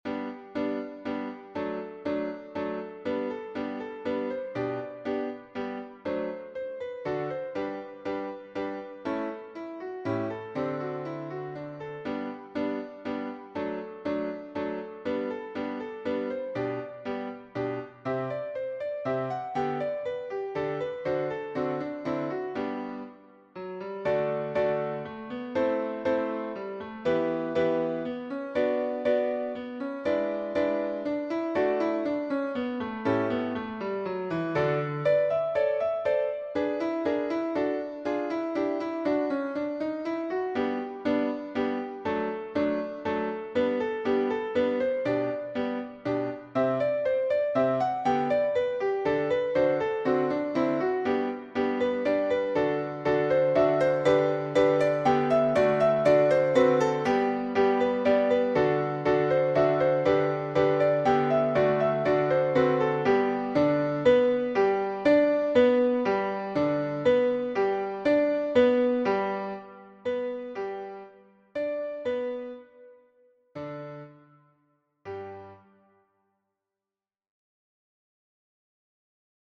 - Œuvre pour chœur à 4 voix mixtes (SATB)
MP3 version piano
Tutti